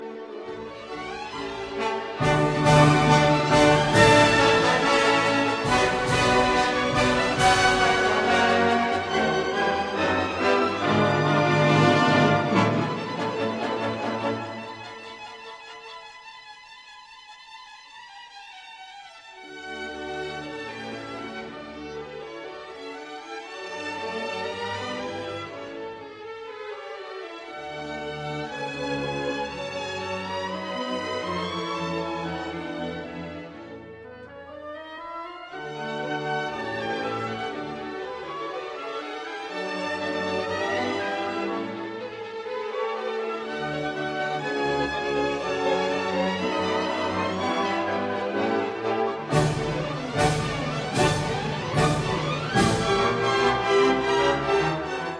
V studni - Ouverture (Prague National Theatre Orchestra, cond.